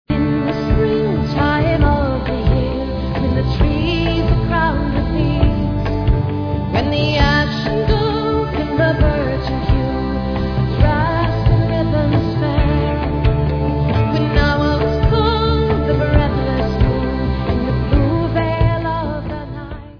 Celtic-worldbeat-folk-pop//special box